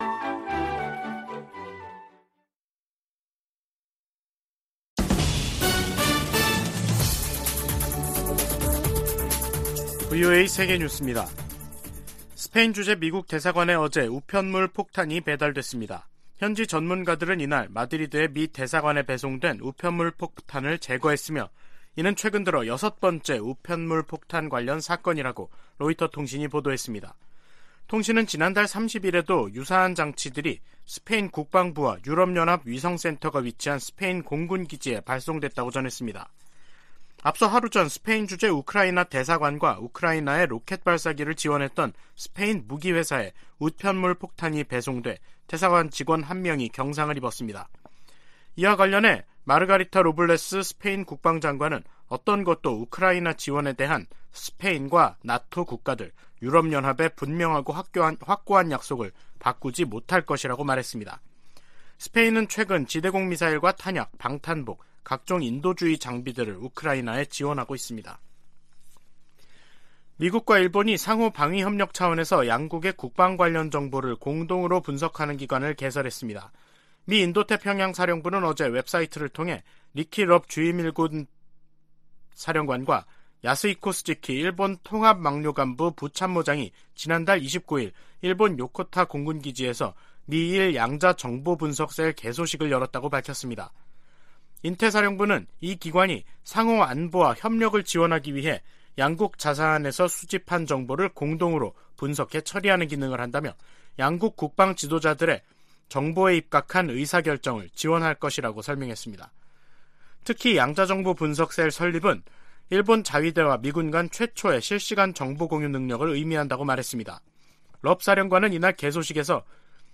VOA 한국어 간판 뉴스 프로그램 '뉴스 투데이', 2022년 12월 2일 2부 방송입니다. 미국 정부가 북한 정권의 잇따른 탄도미사일 발사에 대응해 노동당 간부 3명을 제재했습니다. 한국 정부도 49일만에 다시 북한의 핵과 미사일 개발 등에 관여한 개인과 기관들을 겨냥해 독자 제재를 가하는 등 미한 공조 대응이 강화되고 있습니다.